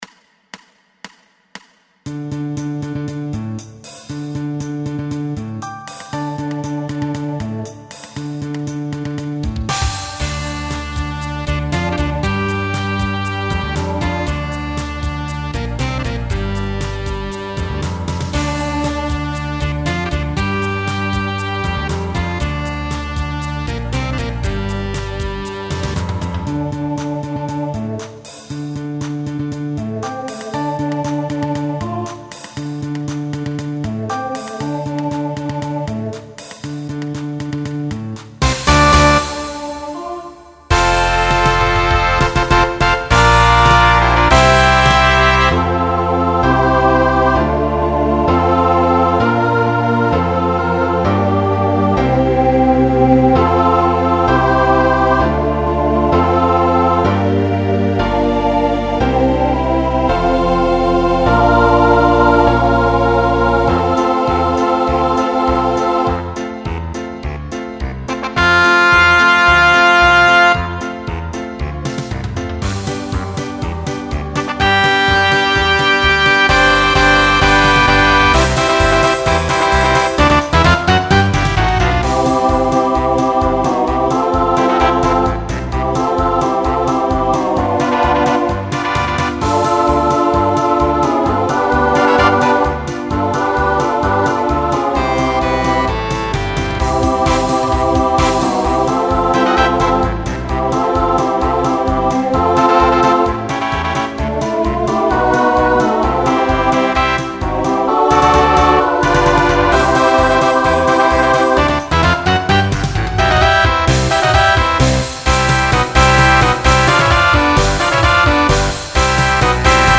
Genre Pop/Dance
Novelty Voicing SATB